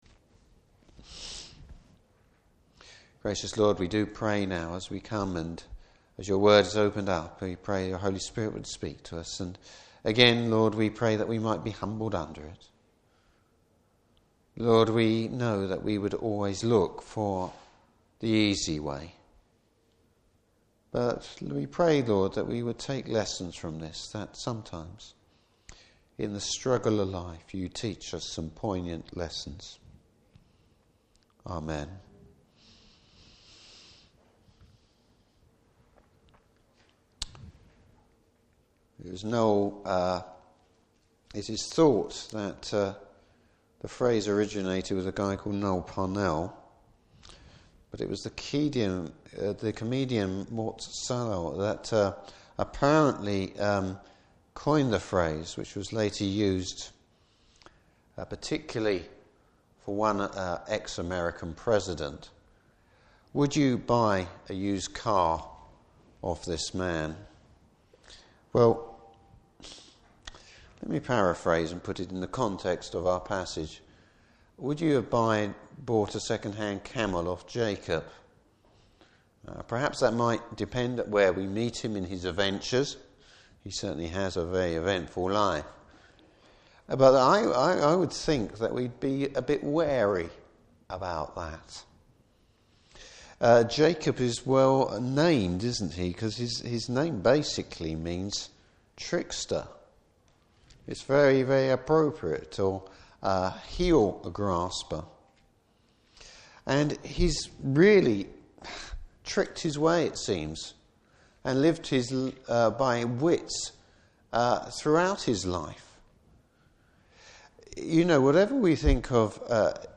Service Type: Evening Service Bible Text: Genesis 32.